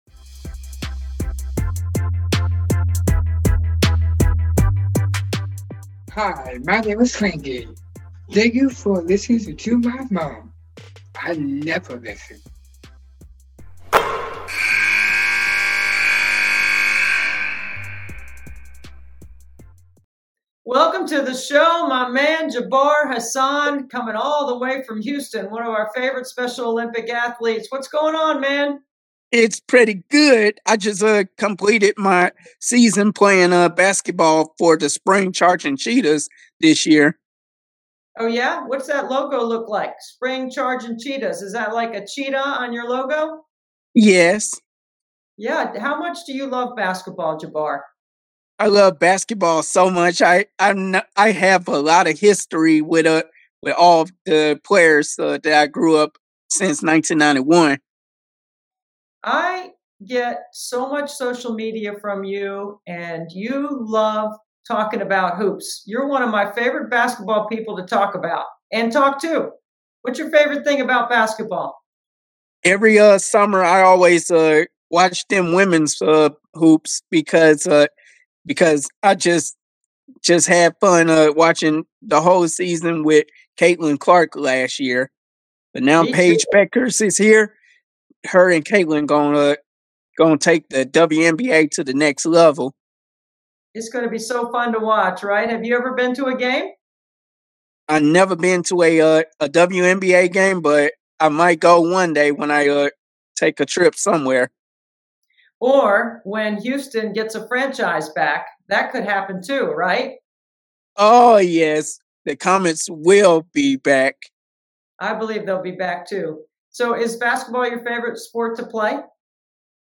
This interview was with Special Olympics Athlete